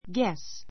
guess 小 A1 ɡés ゲ ス 動詞 ❶ 言い当てる , 推測する guess a riddle guess a riddle 謎 なぞ の答えを言い当てる guess right [wrong] guess right [wrong] 推測が当たる[外れる] Guess what I have in my hand.